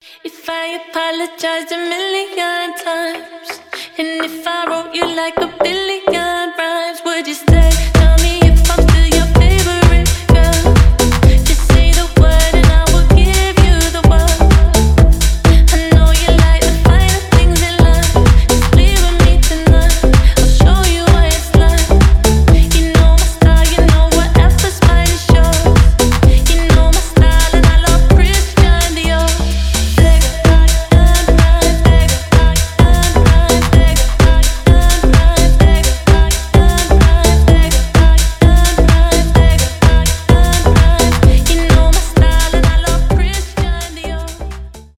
клубные , танцевальные
garage house